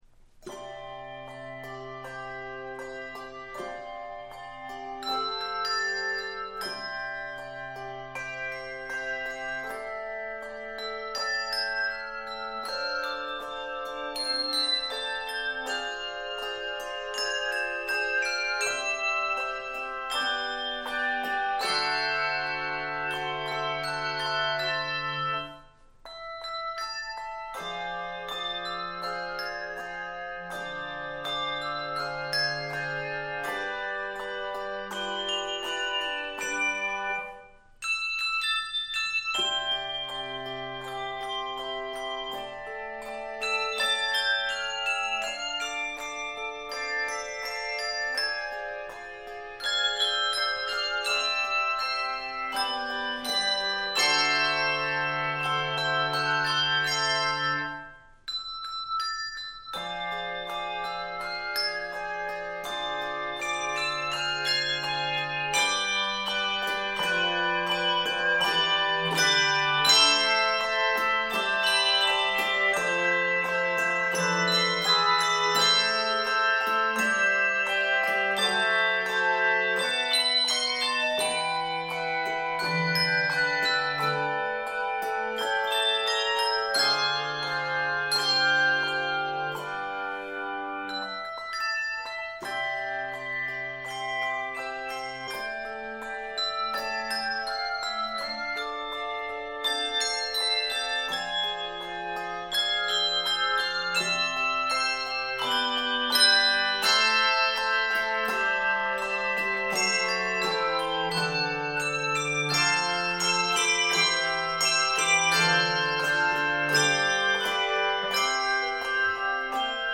handbells
Key of C Major.